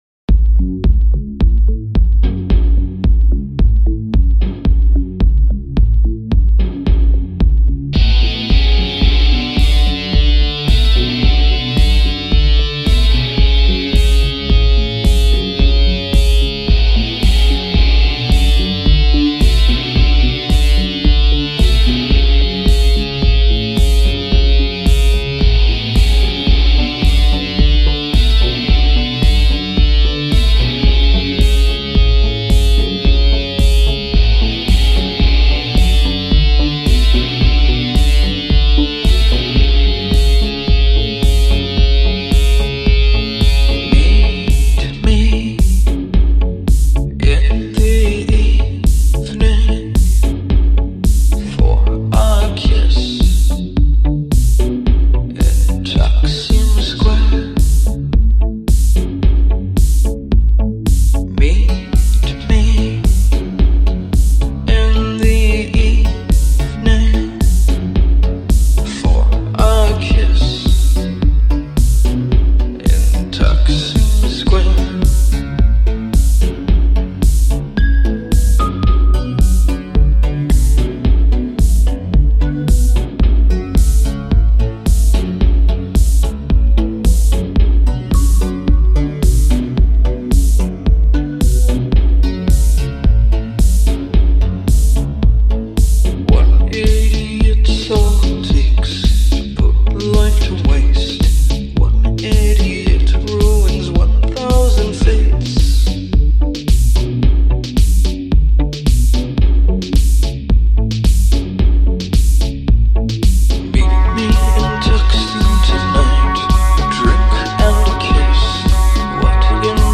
Dance electronic